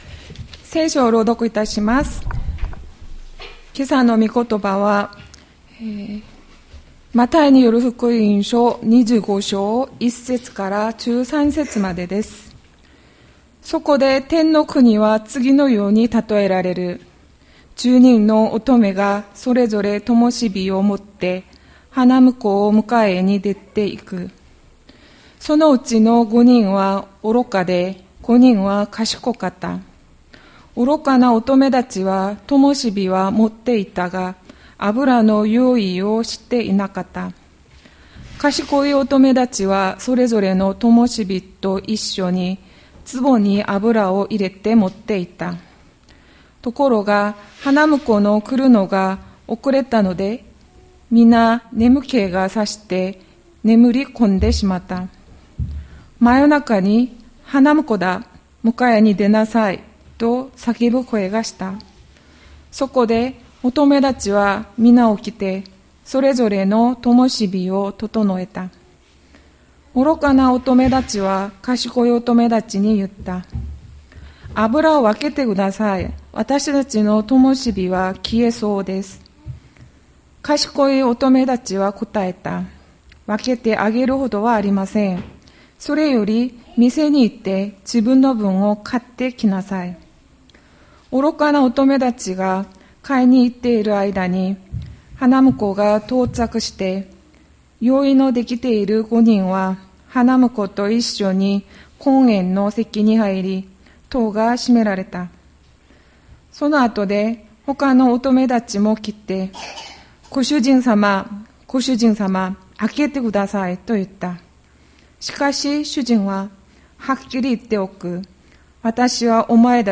千里山教会 2024年04月14日の礼拝メッセージ。